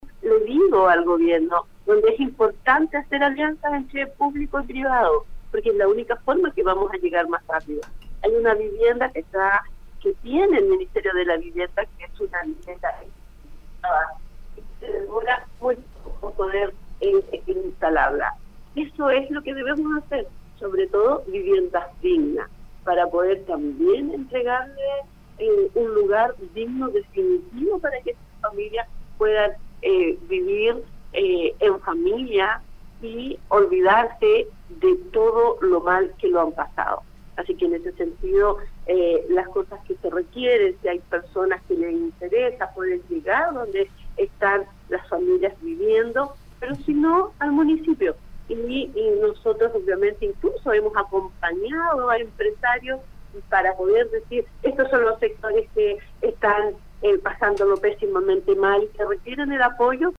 Evaluando el escenario que actualmente enfrenta su comuna, Ivonne Rivas, alcaldesa de Tomé, conversó con Radio UdeC para describir la actual fase de emergencia en la que se encuentran hoy.